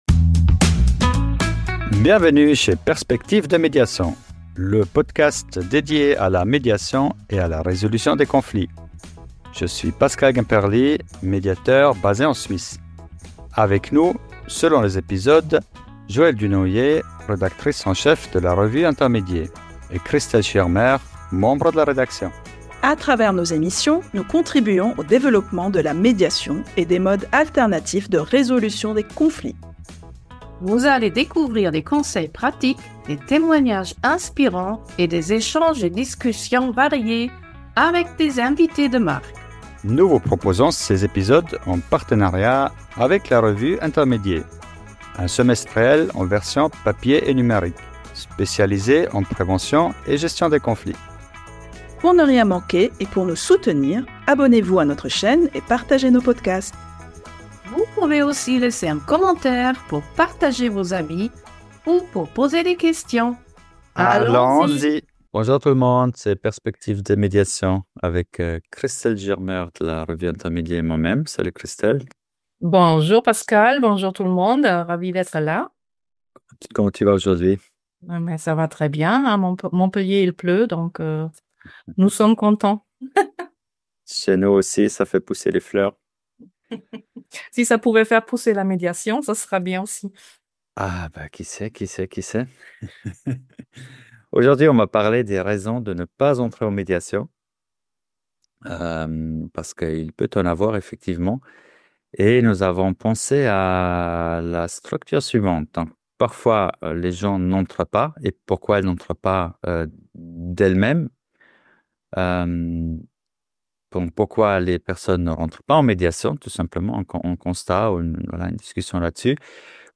Un échange riche, sincère et formateur pour les médiateurs comme pour le grand public.